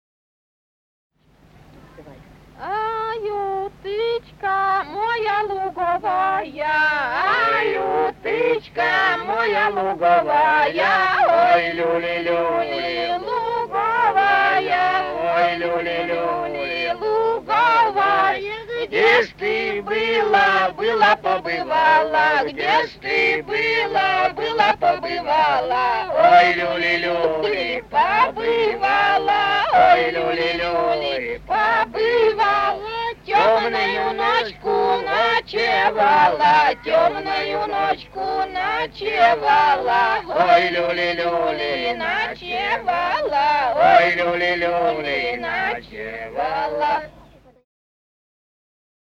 Русские народные песни Владимирской области 10а. Ай, уточка моя луговая (хороводная) д. Галанино Судогодского района Владимирской области.